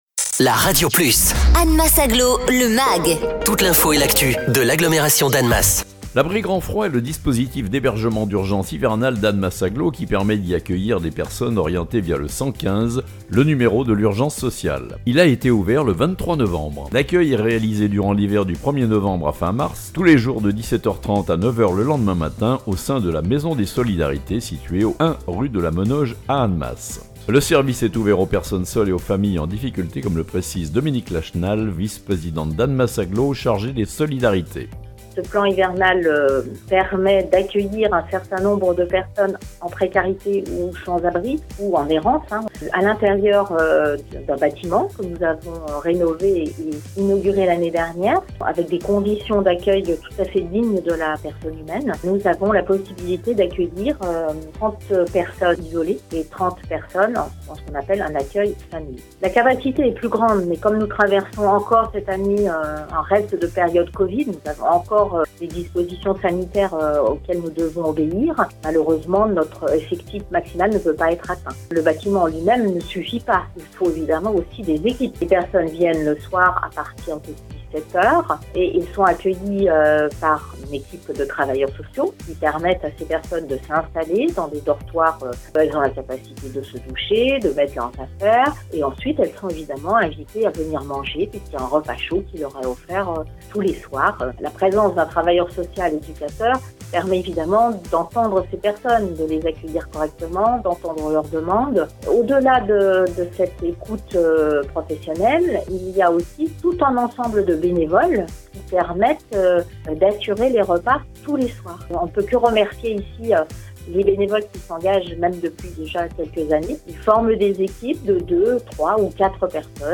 Les explications de Dominique Lachenal, Vice-présidente d'Annemasse Agglo, chargée des solidarités.